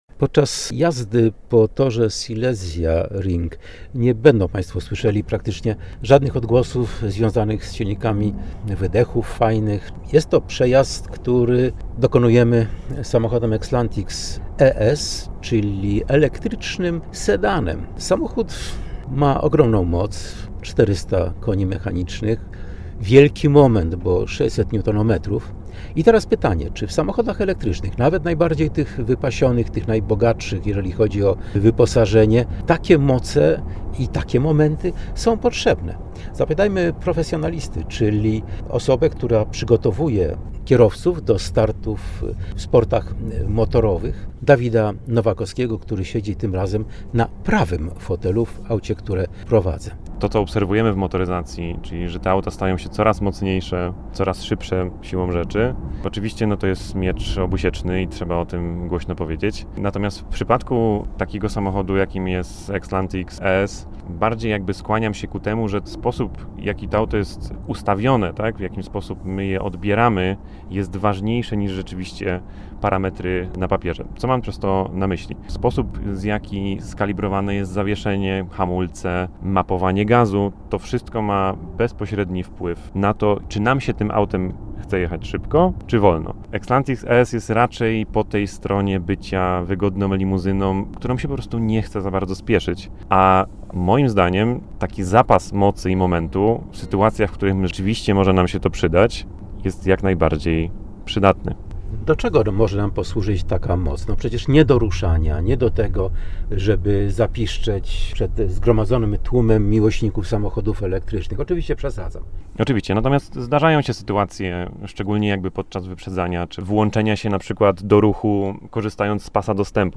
Podczas jazdy po torze Silesia Ring nie będą państwo słyszeli praktycznie żadnych odgłosów związanych z silnikami, fajnych wydechów. Jest to przejazd, który dokonujemy samochodem Exlantics ES, czyli elektrycznym sedanem.